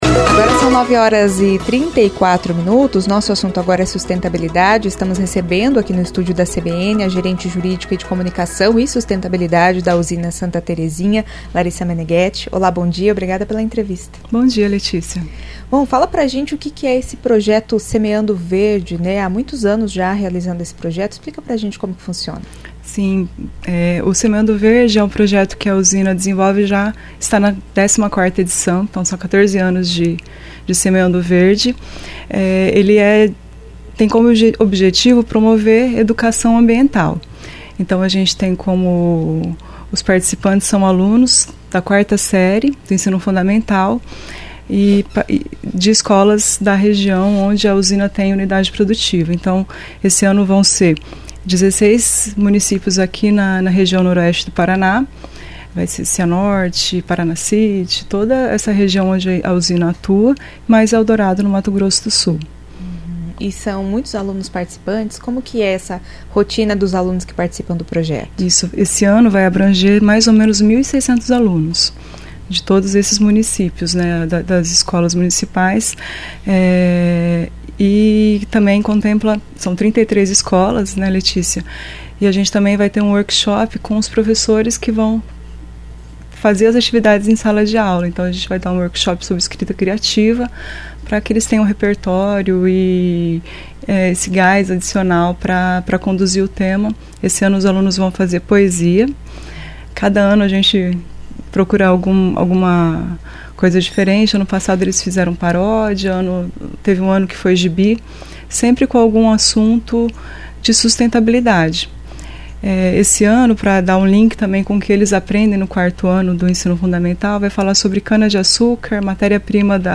Os professores também serão capacitados para a realização do projeto em sala de aula. São 33 escolas participantes em 16 cidades da região noroeste e também em Eldorado (MS). Ouça a entrevista.